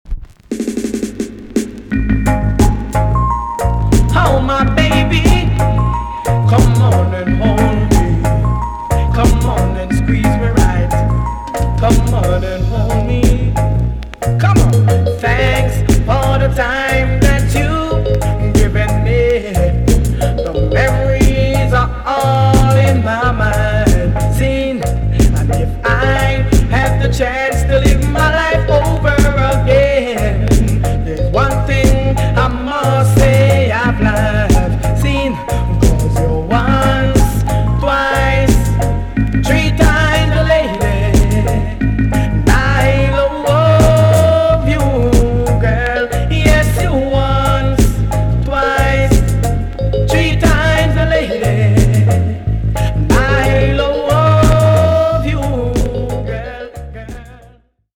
TOP >80'S 90'S DANCEHALL
EX-~VG+ 少し軽いチリノイズが入りますがキレイです。